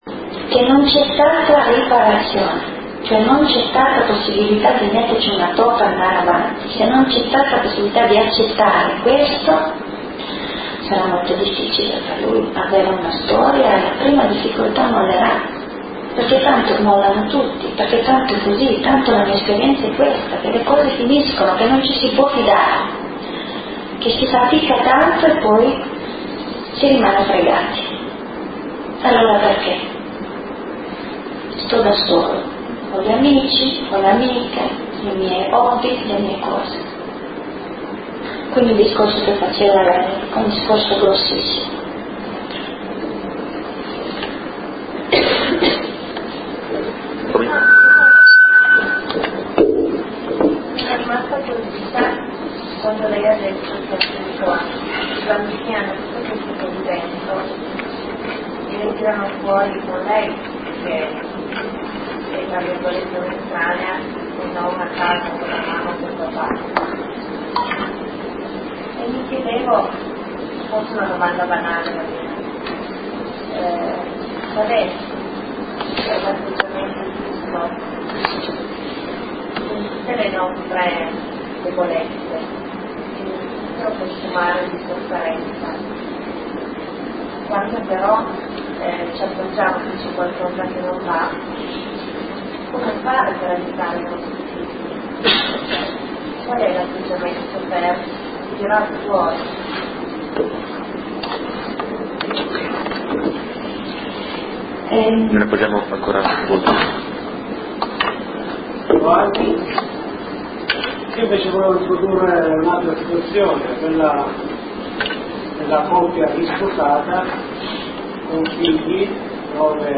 viene proposta una serata di preghiera e approfondimento